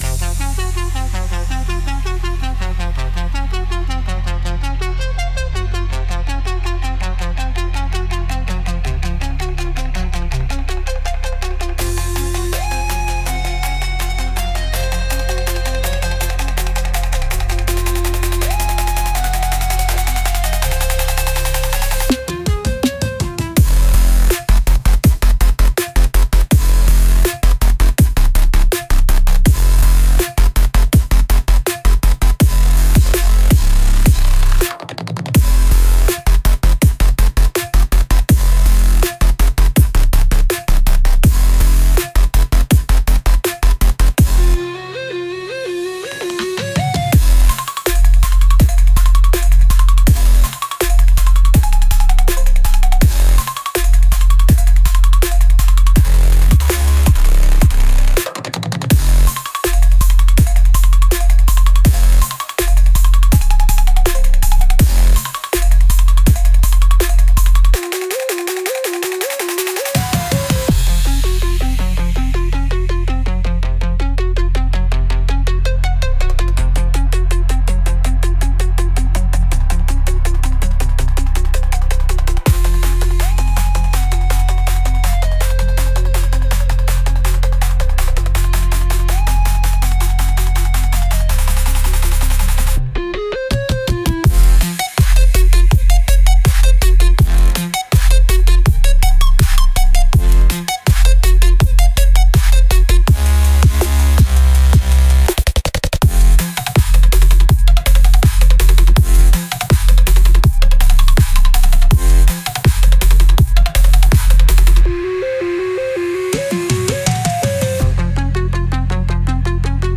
Instrumental - Fire tongue Funk